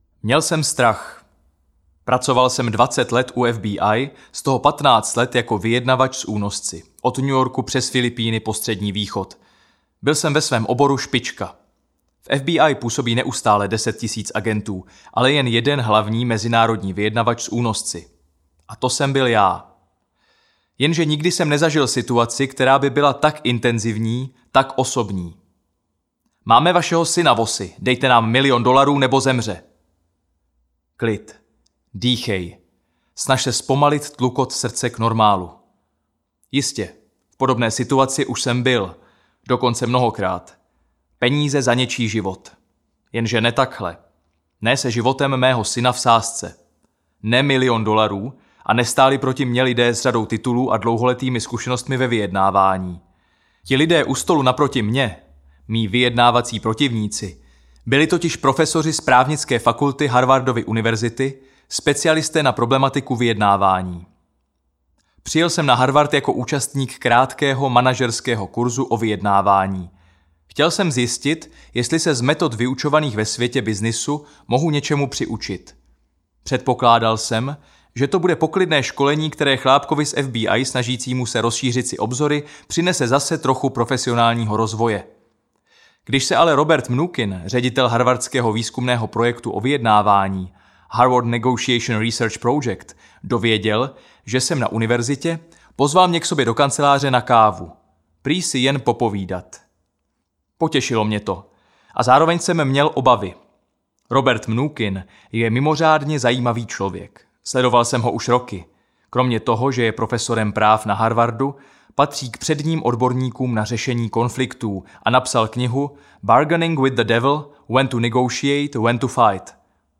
Audio knihaNikdy nedělej kompromis
Ukázka z knihy